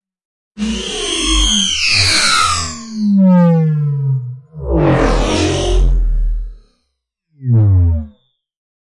外星人犀牛F X " 犀牛02 - 声音 - 淘声网 - 免费音效素材资源|视频游戏配乐下载
多次飞翔。